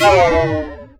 SCIFI_Down_03_mono.wav